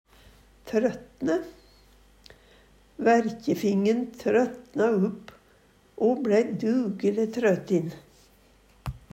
trøtne - Numedalsmål (en-US)